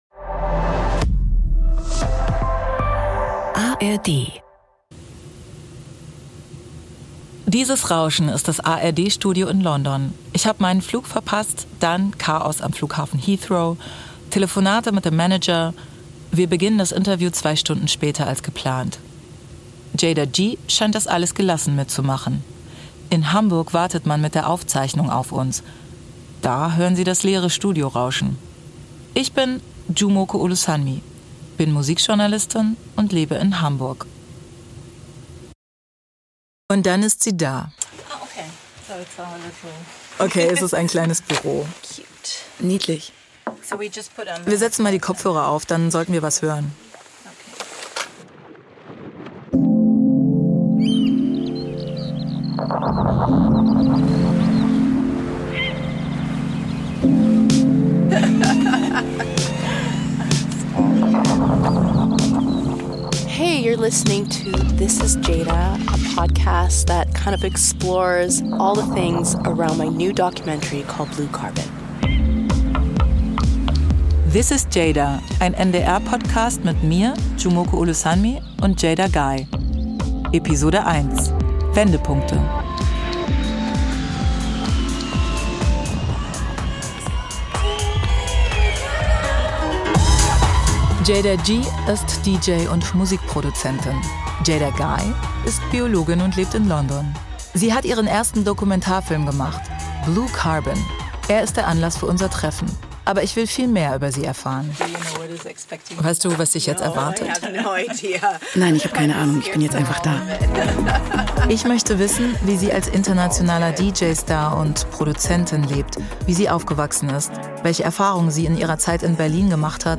Deutsche Stimme
Aufzeichnung des Interviews